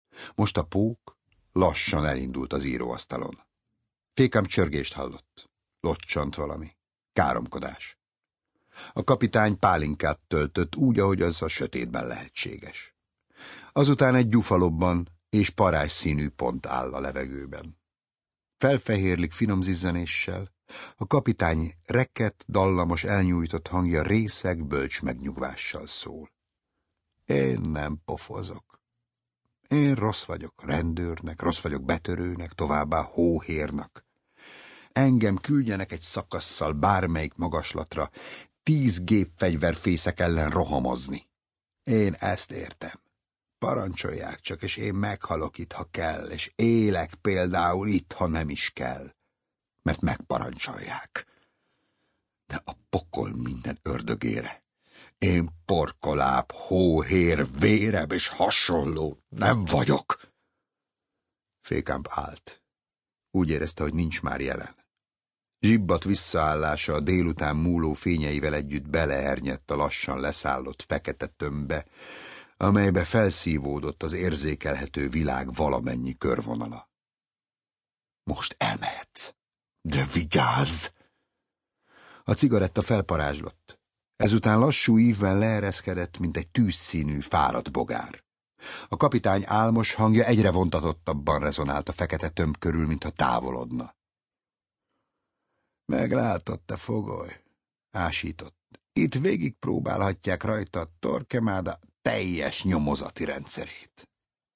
Csontbrigád (Online hangoskönyv